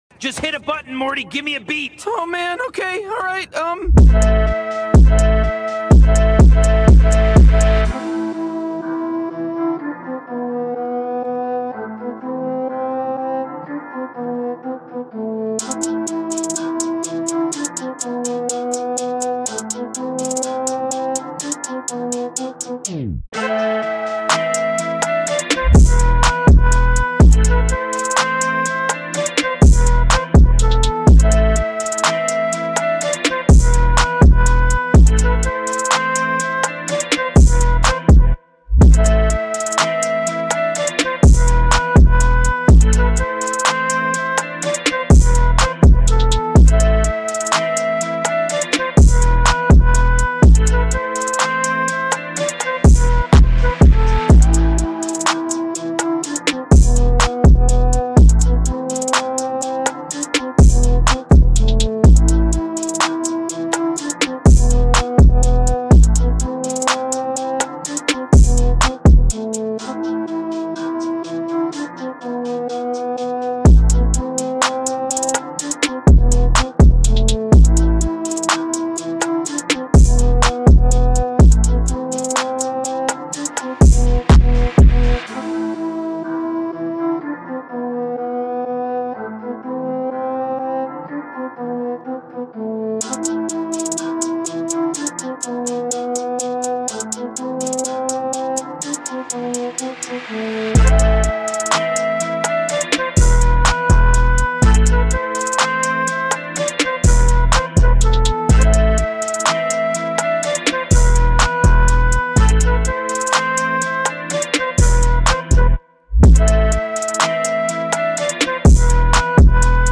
بیت هارد ترپ
ژانر : ترپ مود : مامبل | گنگ | فلو بازی تمپو : 124 زمان